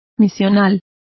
Complete with pronunciation of the translation of missionary.